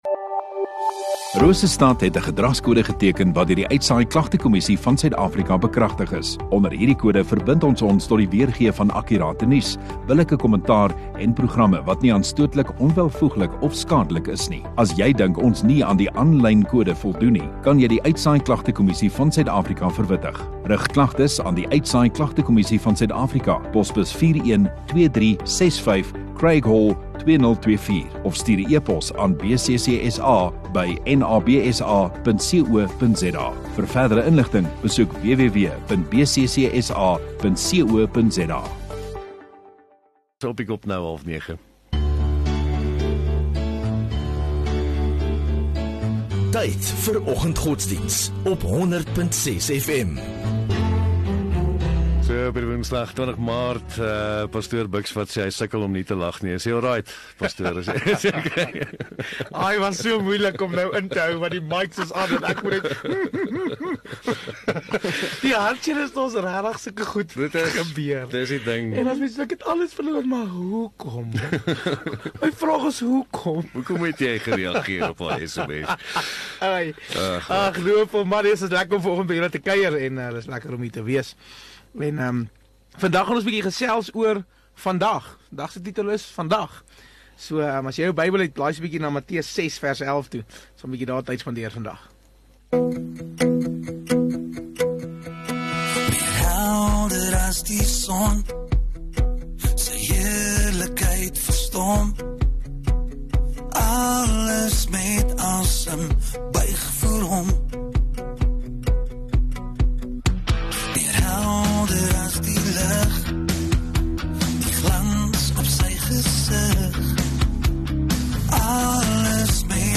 Rosestad Godsdiens